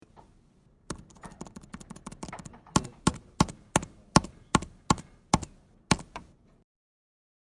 办公室家居噪音 " 钢笔书写
描述：办公室的声音
Tag: 办公 点击